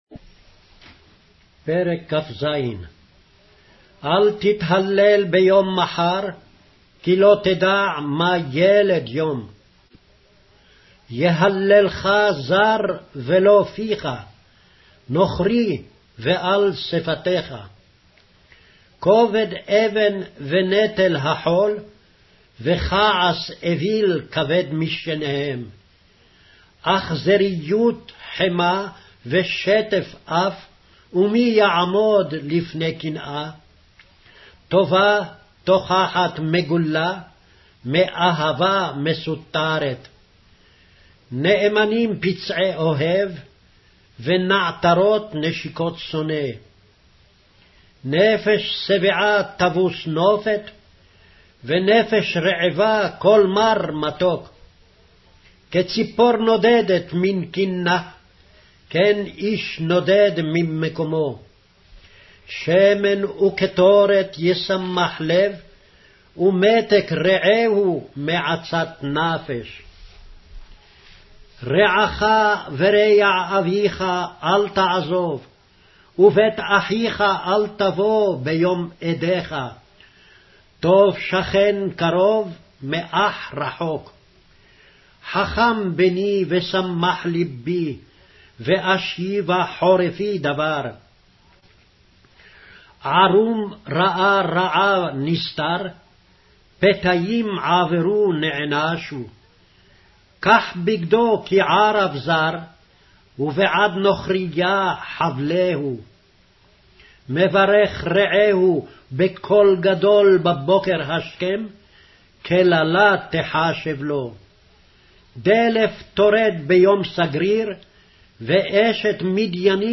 Hebrew Audio Bible - Proverbs 6 in Erven bible version